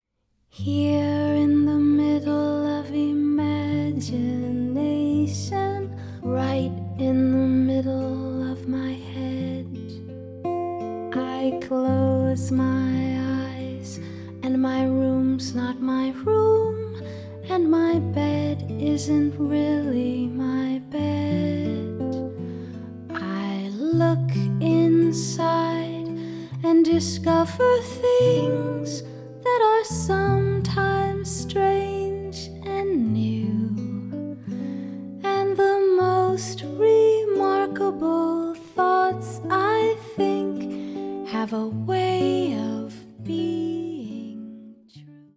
カナダ人ジャズ・シンガー